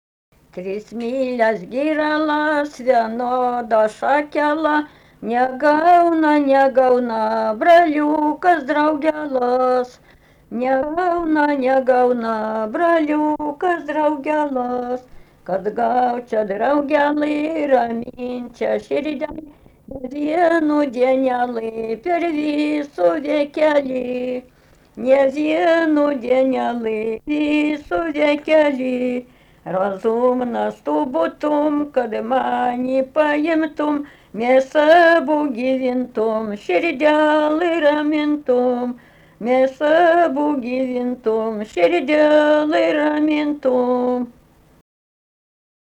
daina
Stirniai
vokalinis